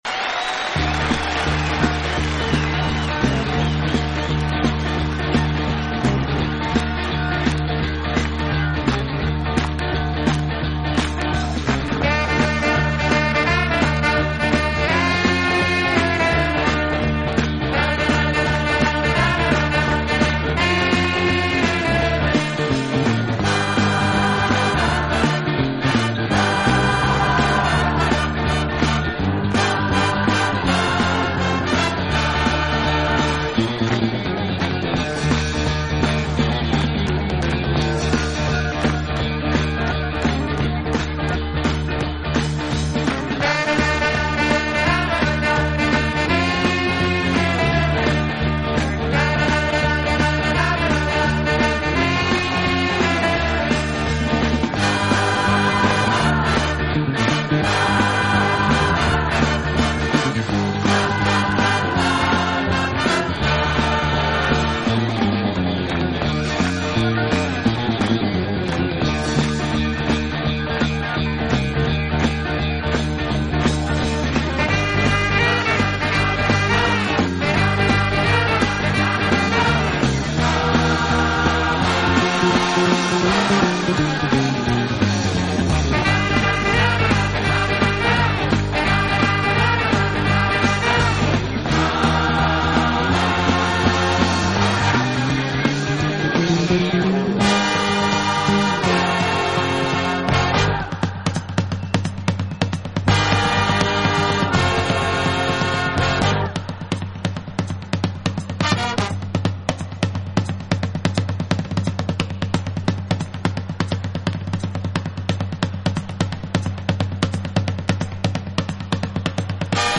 【轻音乐】
以热烈的旋律，独特的和声赢得千百万听众
此外，这个乐队还配置了一支训练有素，和声优美的伴唱合唱队。